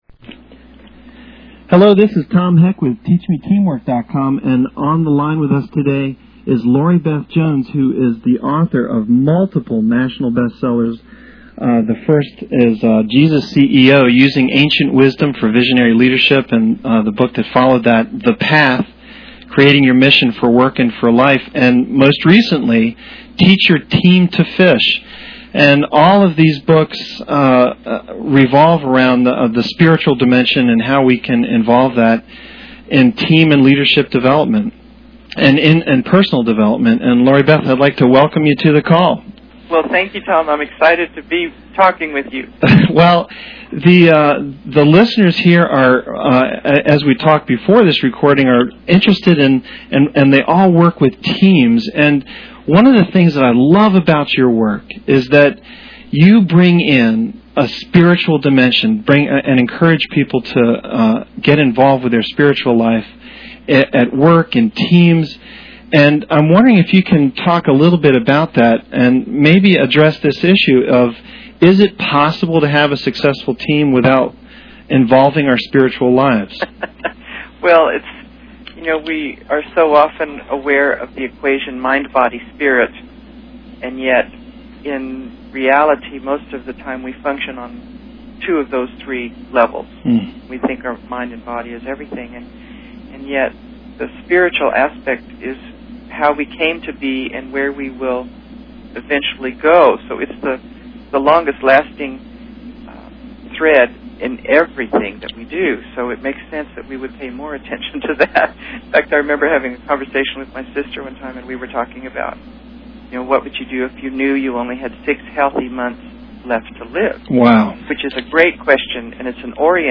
Download laurie_beth_jones_mp3.mp3 Note: The first minute or so of the interview is a little garbled.